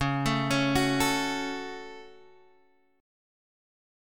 C# Suspended 4th Sharp 5th